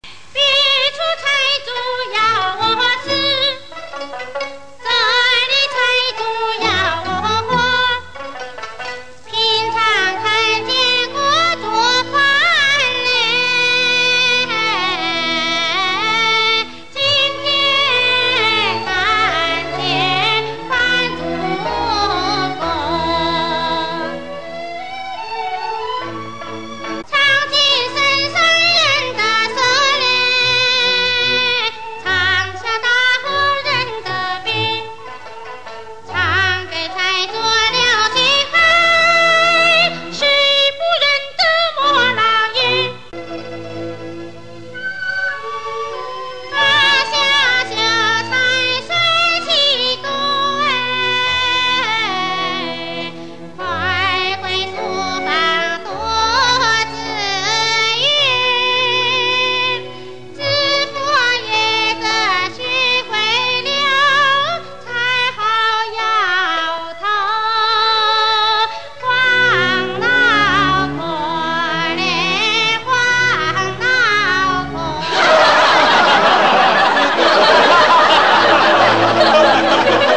她演唱风格鲜明，独具个性，是不可多得的壮乡歌唱家。